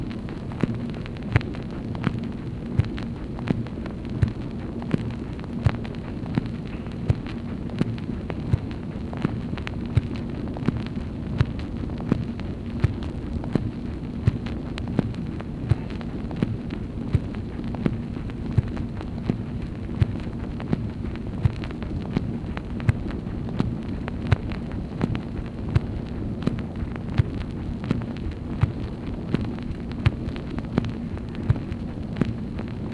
MACHINE NOISE, March 6, 1964
Secret White House Tapes | Lyndon B. Johnson Presidency